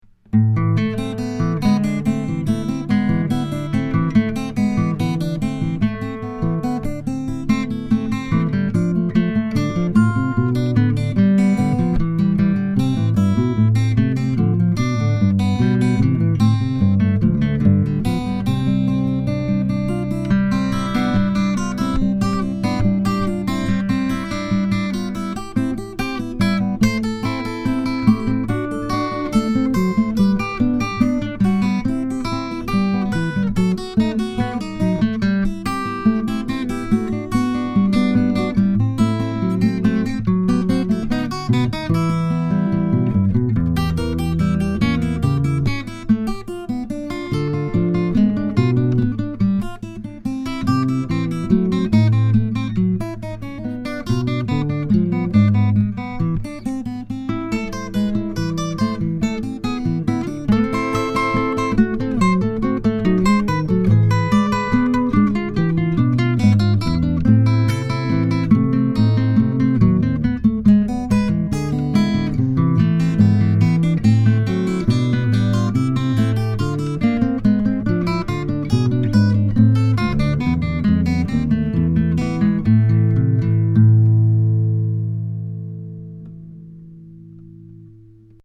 transposition: octave on right hand
duet for 2 guitars (pdf) (
invention13guitar.mp3